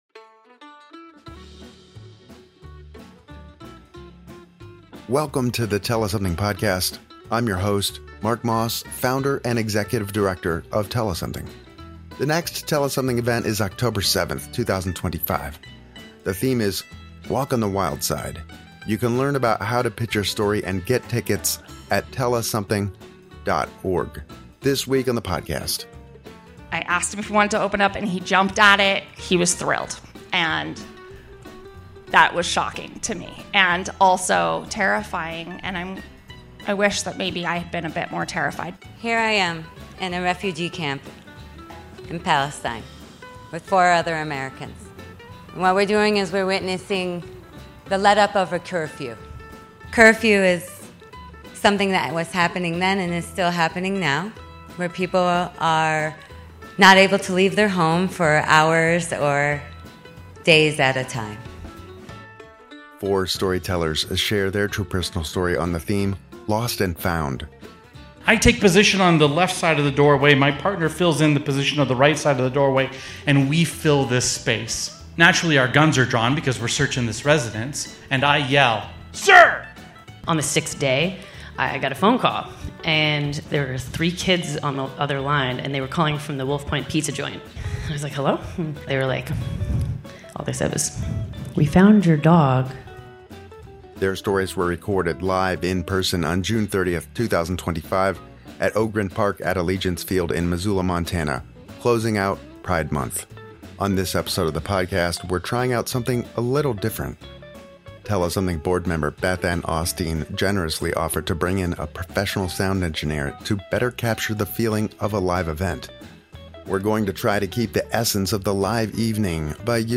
Live in person and without notes.